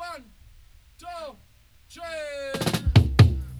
134-FILL-DRY.wav